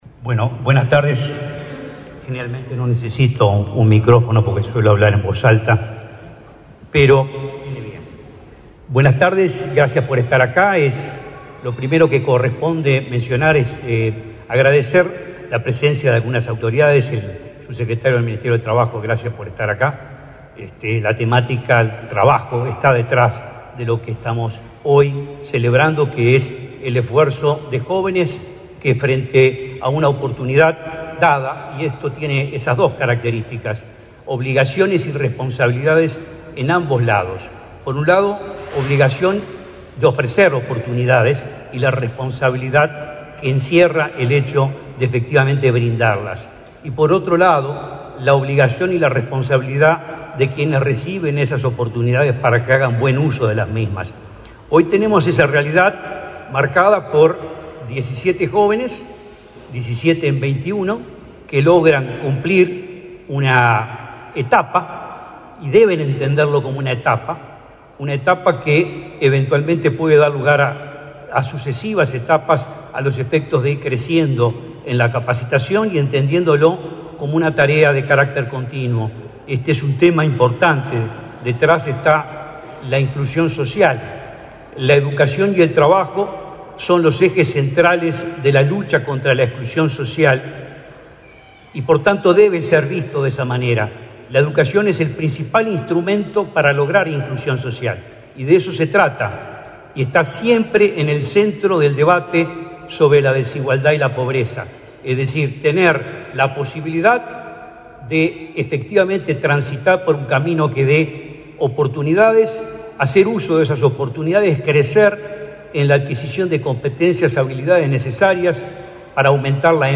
Palabras del presidente del INAU, Guillermo Fossati
En el evento disertó el presidente de INAU, Guillermo Fossati.